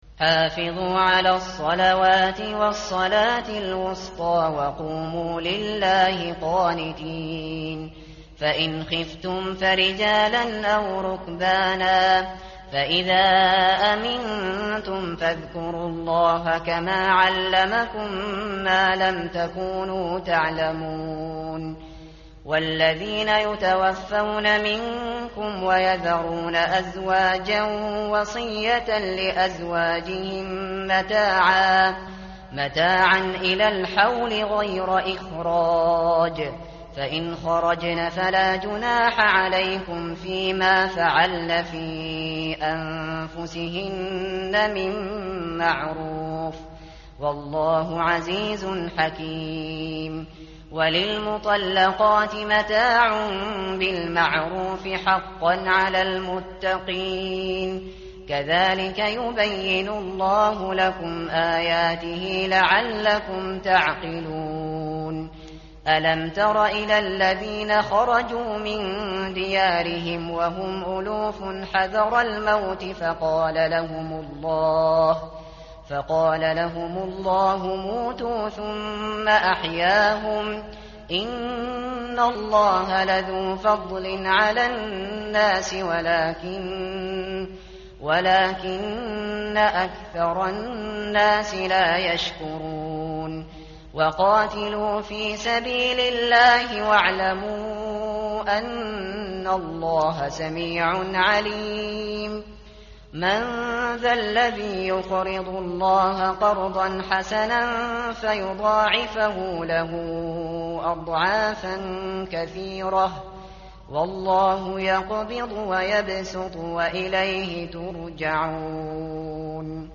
tartil_shateri_page_039.mp3